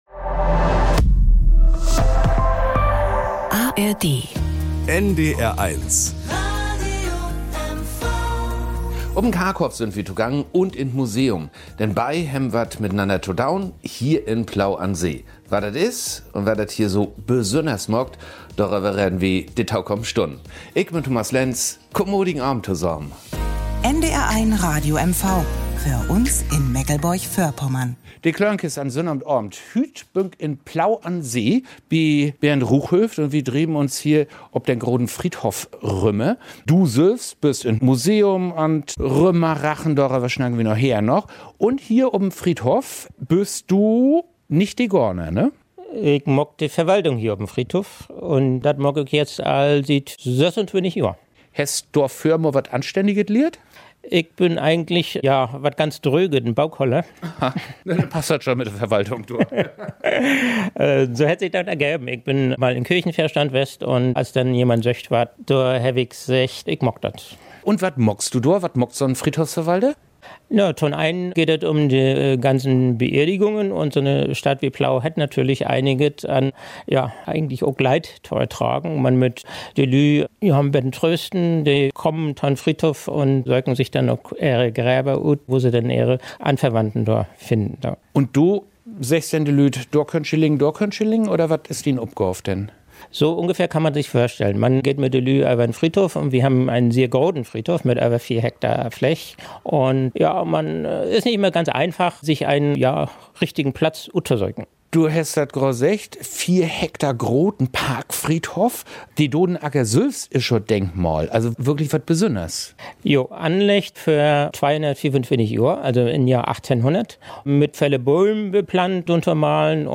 bei einem Spaziergang über den Parkfriedhof der Stadt. Im Museum finden sie ein tiefes Verlies und einen ebenso tiefen Blick in die Geschichte von Plau am See.